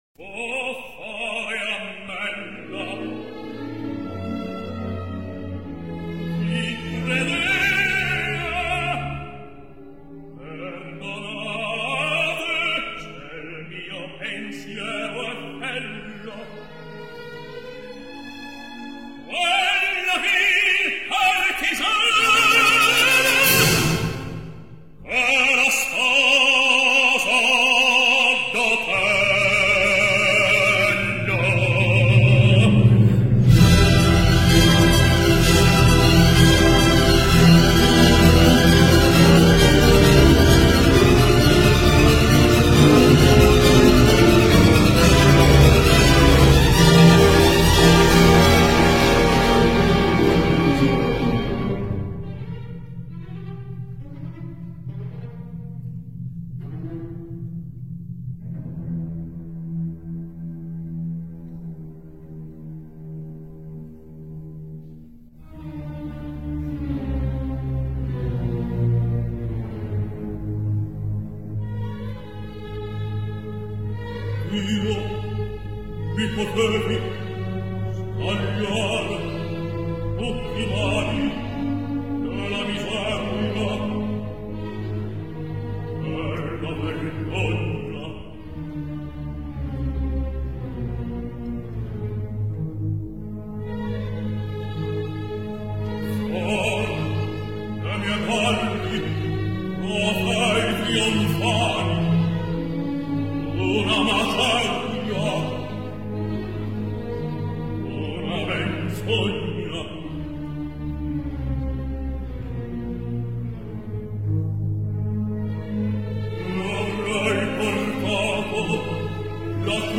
Lion de Pesaro : le titre de l’article peut paraître insolite, ou inapproprié, pour un ténor italien qui ne chanta guère Rossini.